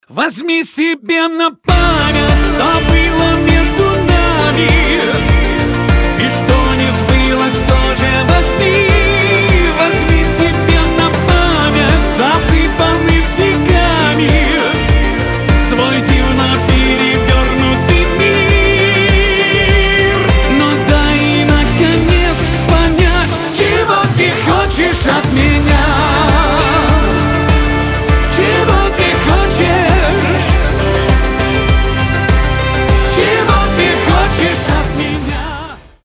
• Качество: 320, Stereo
поп
мужской вокал
громкие